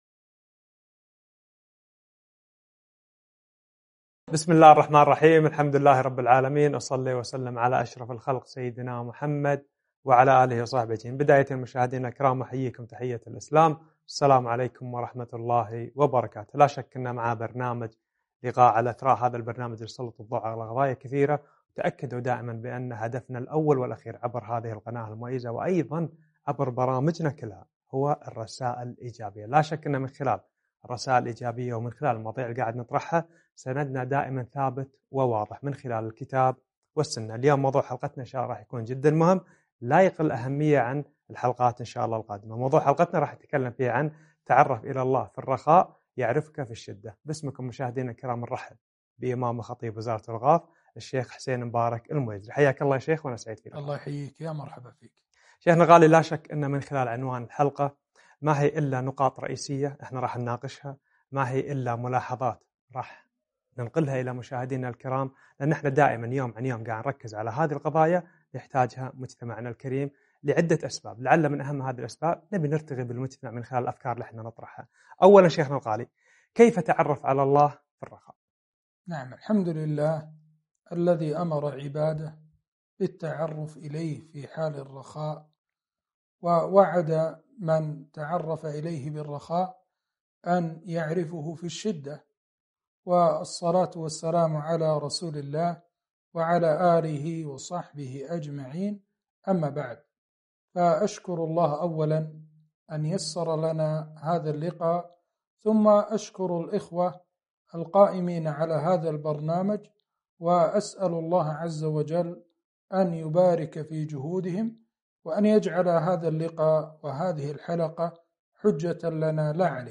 تعرف إلى الله في الرخاء يعرفك في الشدة - لقاء على قناء إثراء